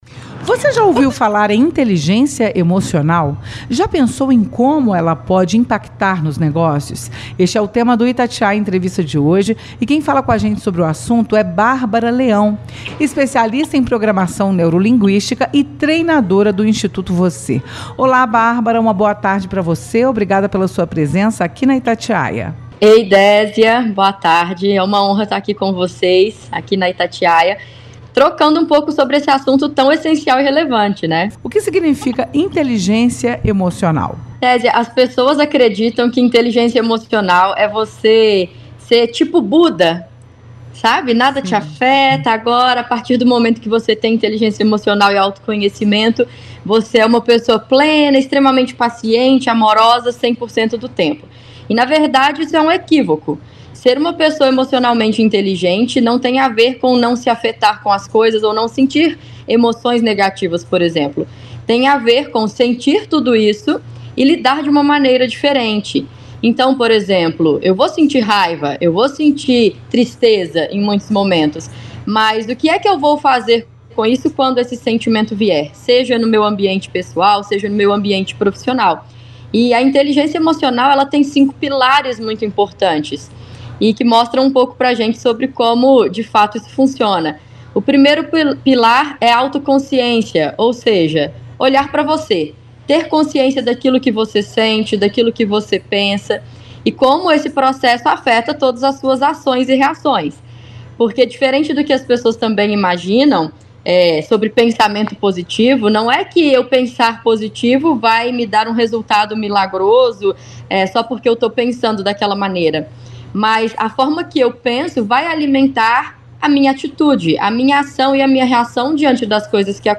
Itatiaia Entrevista